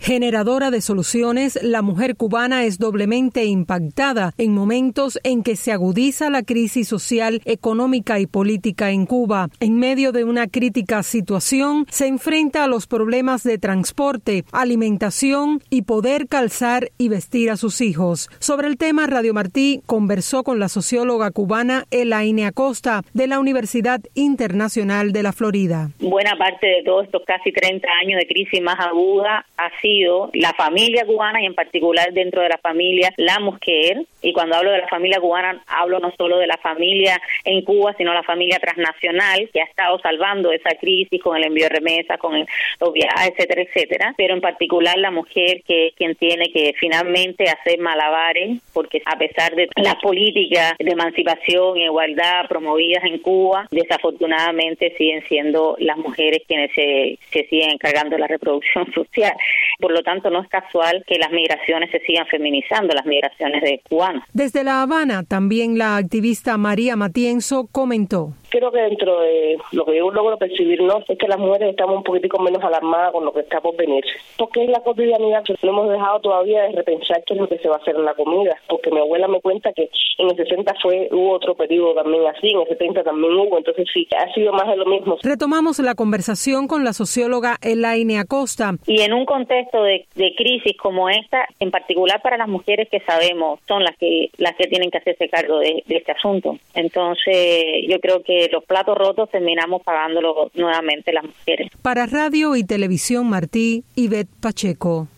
reporte con opiniones sobre el tema